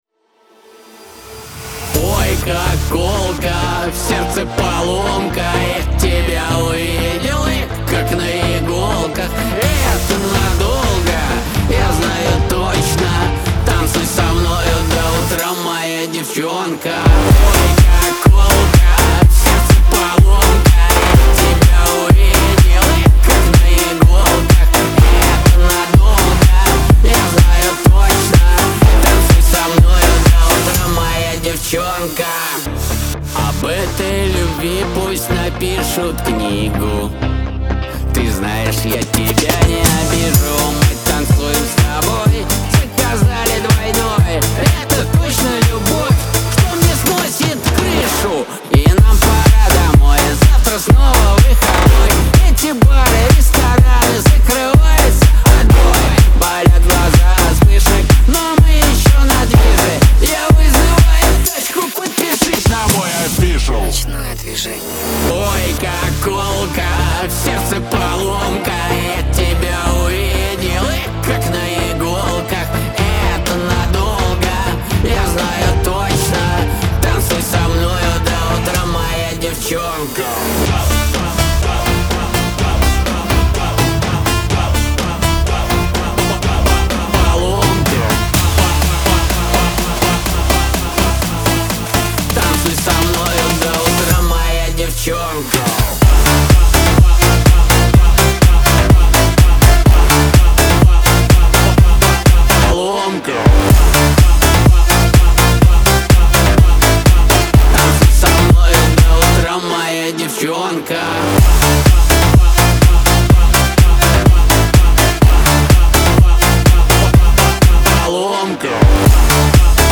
весёлая музыка
эстрада , танцевальная музыка
диско